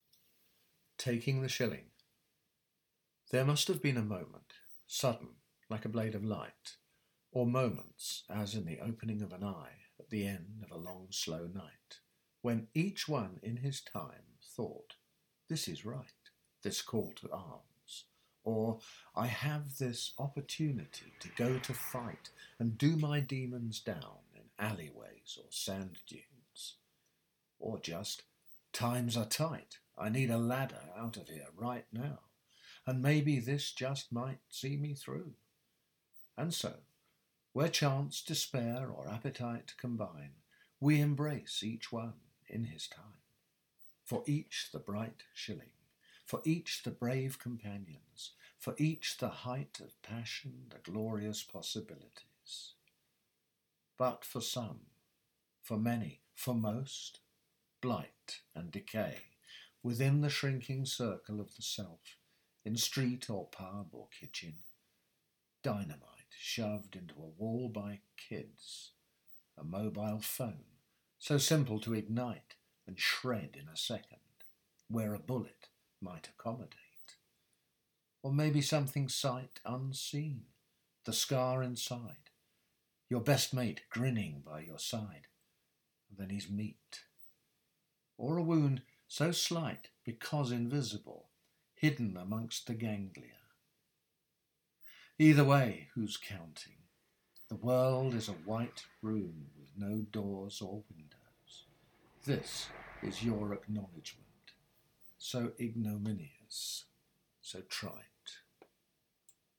This poem works on a repeated every-other-line full rhyme.